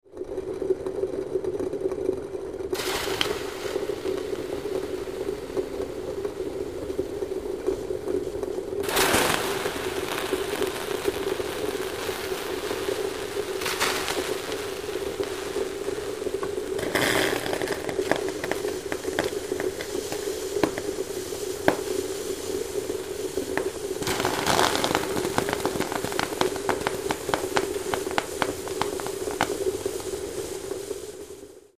Boiling Pot 1; Water Boiling Over; Occasional Crackles, Pops, And Sizzles. Constant Boiling Rumble In Background. Close Perspective. Kitchen, Restaurant.